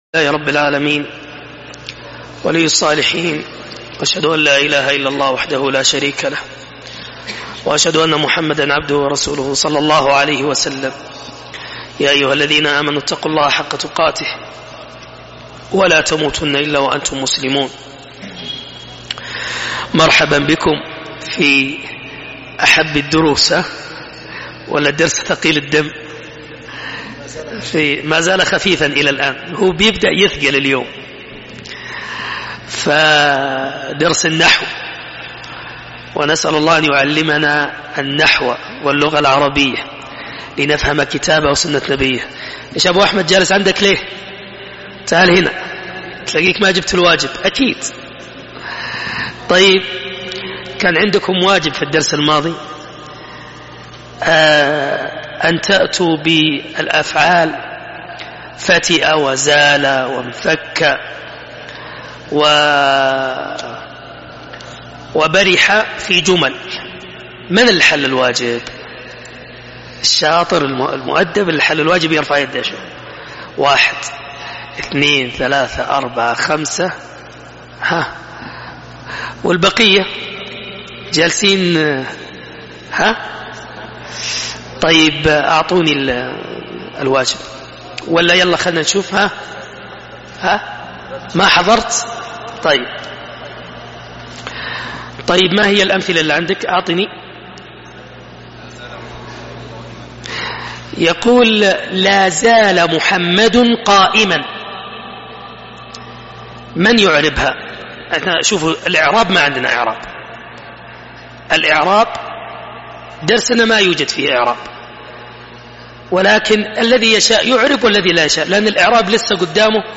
دروس وسلاسل دروس